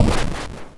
Explosion5.wav